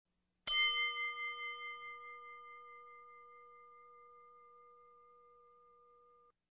Любой звук колокольчика, корабельного, музыкального, маленького, большого, одиночные удары или непрерывный звон.
Категория: Колокола и колокольчики
Колокольчик №2